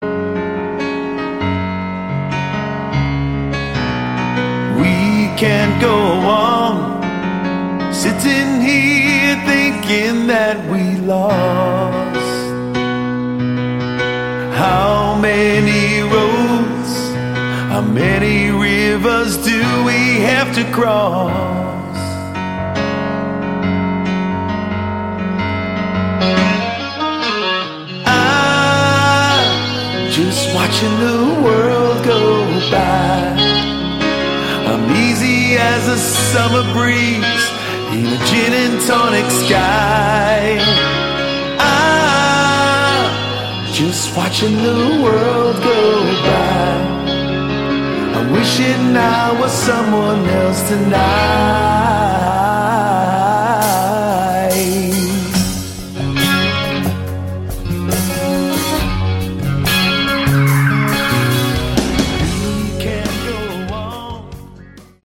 Category: AOR
guitar and vocals